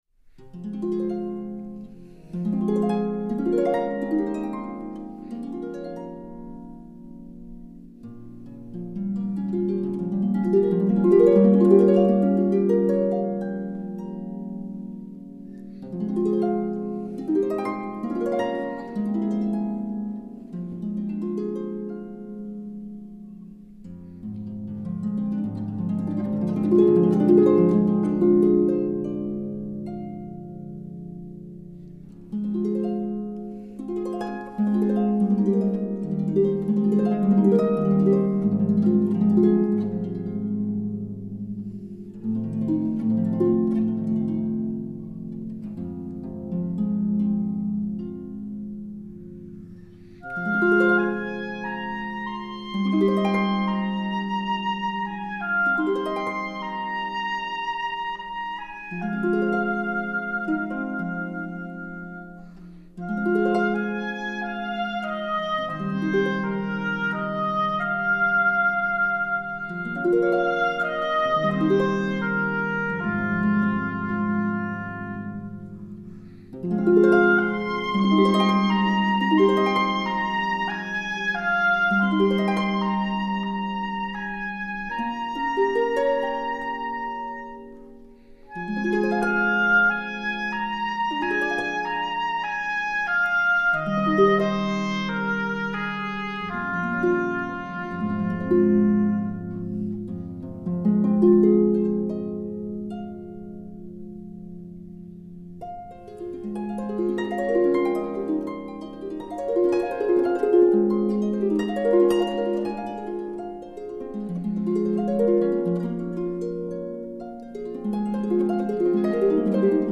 类型: 民乐雅韵